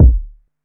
Urban Kick 01.wav